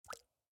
drip_water7.ogg